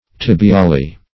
Search Result for " tibiale" : The Collaborative International Dictionary of English v.0.48: Tibiale \Tib`i*a"le\, n.; pl.